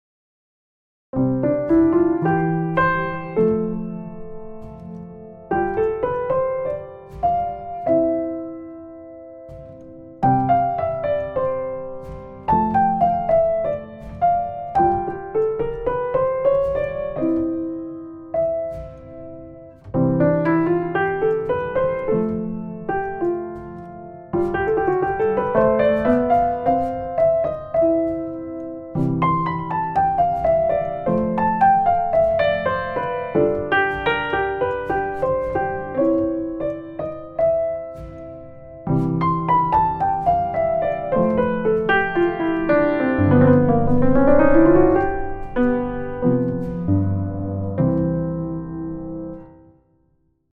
C-Dur  1-4-5-1 和音の繰り返しでメロディを弾く
とてもシンプルなコード進行で、音楽の超基本。
ここでのメロディは、ショパン風にして遊んでみました。
C Major (C-Dur): Playing a melody over repeated 1–4–5–1 chords
For the melody, I played in a Chopin-like style.